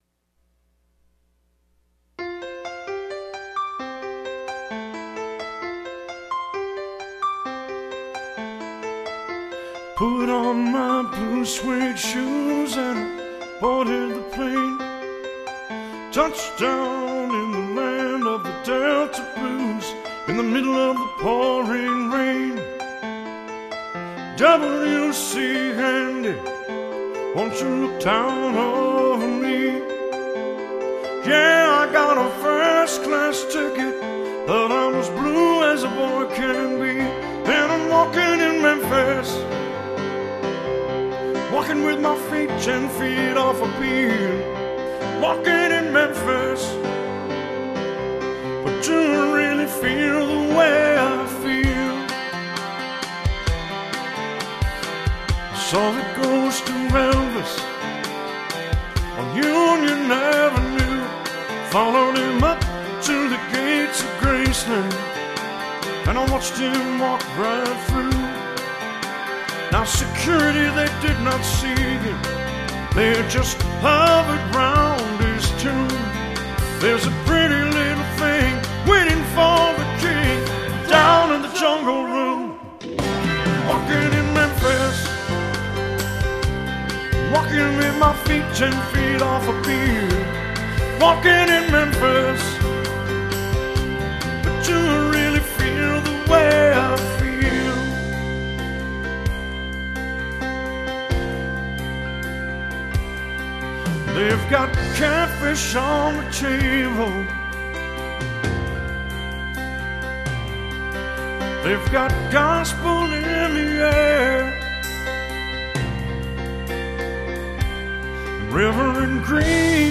80's, 90's & 00's Music